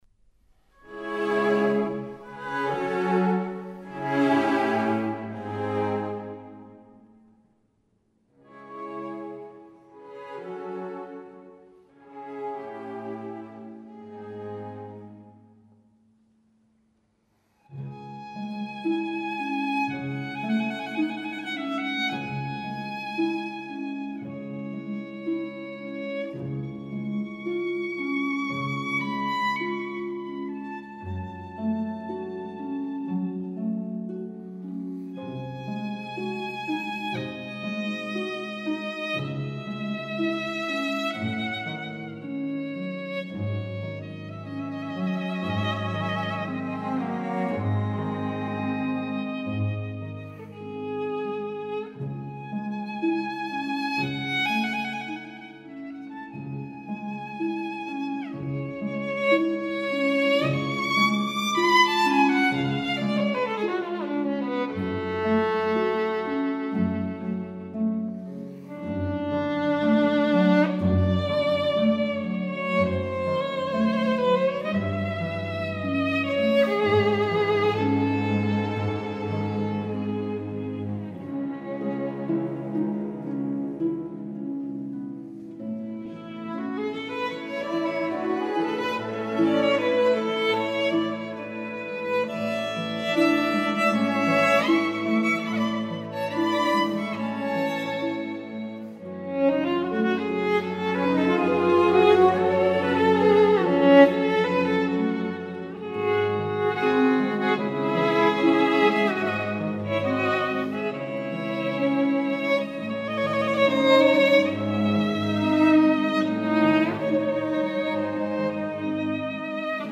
附一首由钢琴改编的小提琴曲，感受钢琴诗人的爱情故事